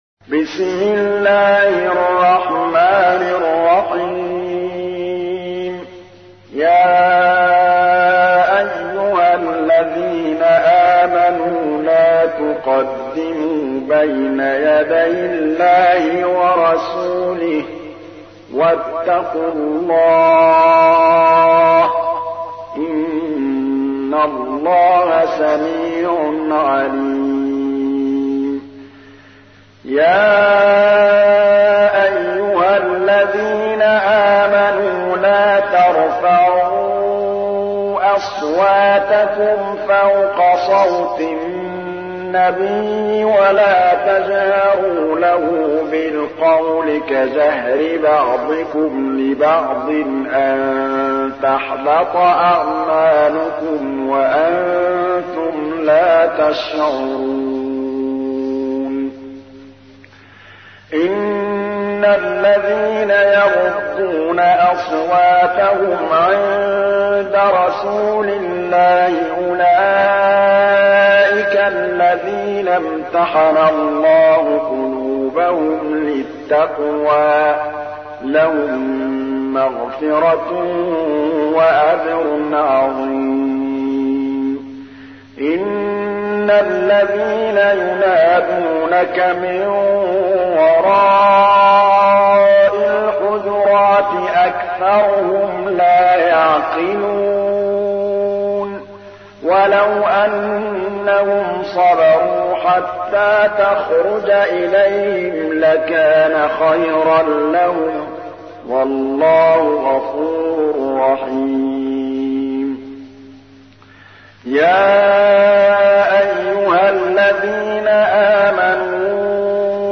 تحميل : 49. سورة الحجرات / القارئ محمود الطبلاوي / القرآن الكريم / موقع يا حسين